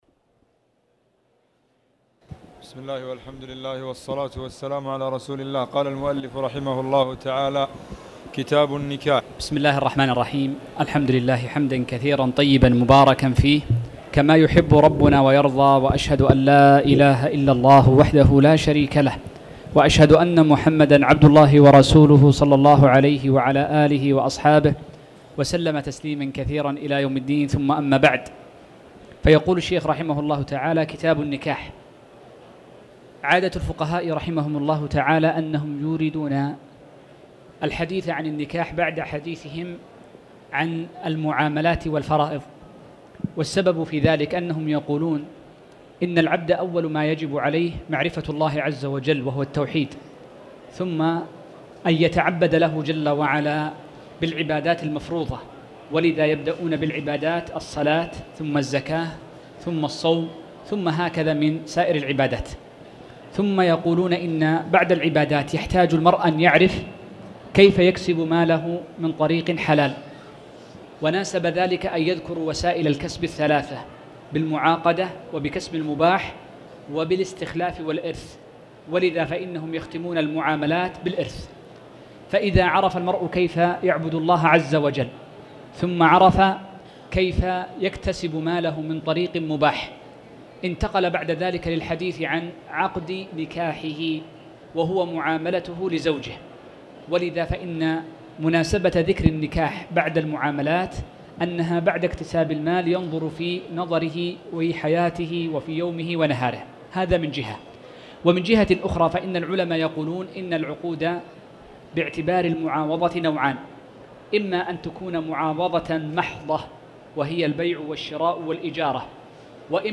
تاريخ النشر ١١ ربيع الثاني ١٤٣٩ هـ المكان: المسجد الحرام الشيخ